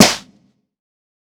TC3Snare14.wav